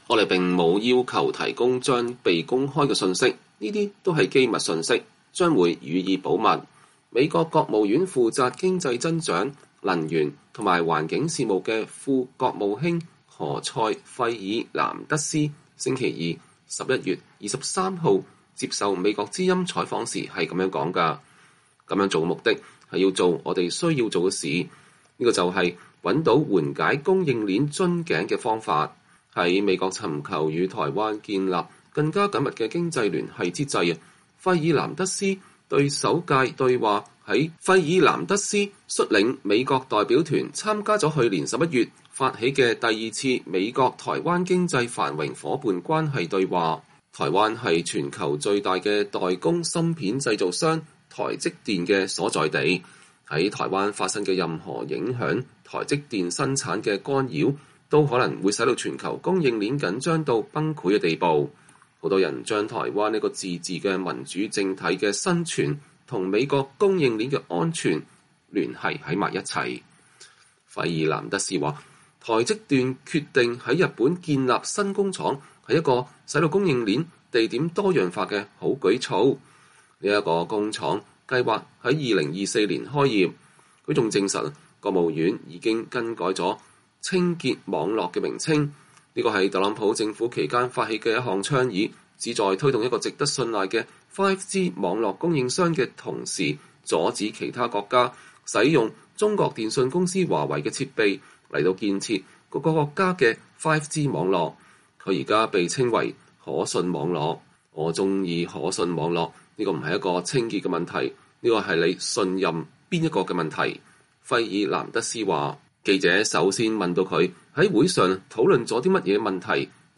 VOA專訪：美副國務卿談美台對話、芯片短缺和5G“可信網絡”